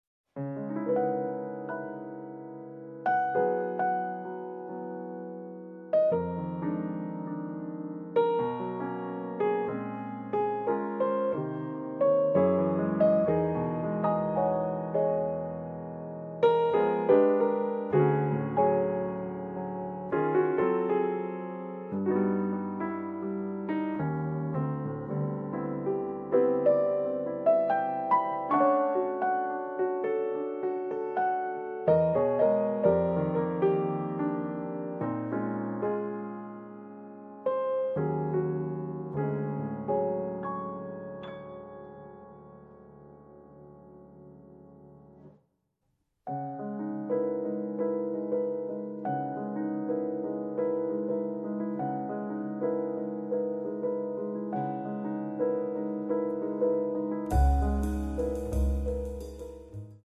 sax soprano, tenore, clarinetto
pianoforte
contrabbasso
batteria
si muovono all'interno della tradizione jazzistica europea